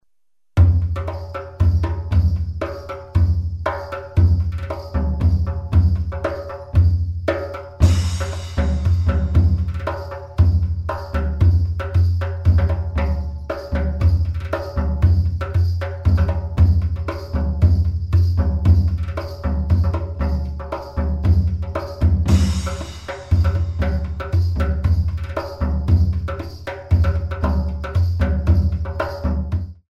mm=118